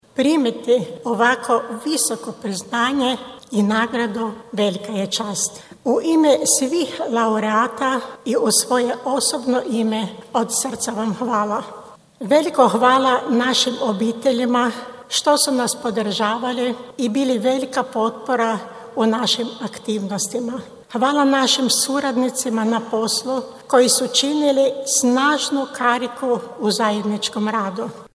Svečana sjednica Skupštine Međimruske županije održana u atriju Staroga grada u Čakovcu bila je središnji događaj obilježavanja Dana Međimurske županije.
Svečana sjednica uz prigodne govore bila je prilika za pregled prethodine godine, ali i za dodjelu nagrada zaslužnim Međimurkama i Međimurcima.